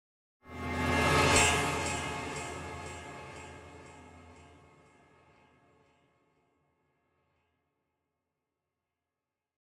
びっくり効果音1
1つ目は、恐怖や鳥肌というイメージで作成したびっくり効果音です。
ホラー系のシーンで使えそうかなと思います。